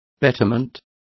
Complete with pronunciation of the translation of betterments.